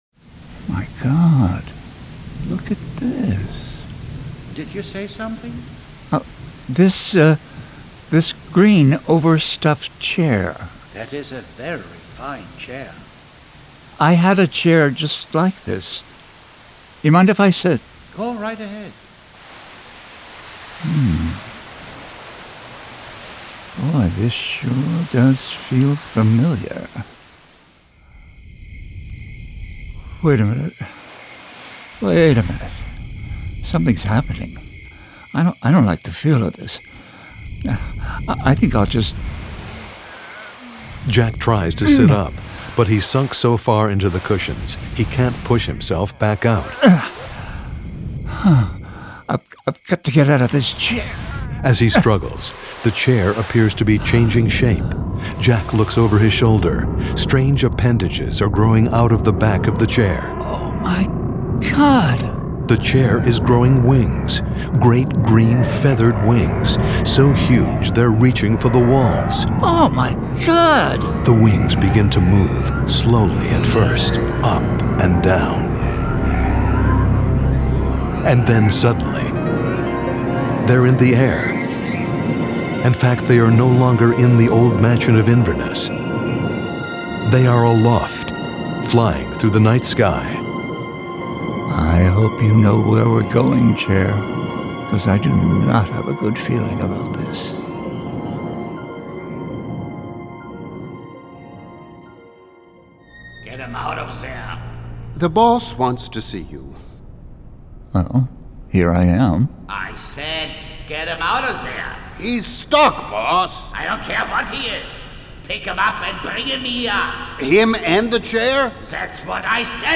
For fans of radio drama and exotic metaphysical mysteries. 12 approximately ten-minute episodes.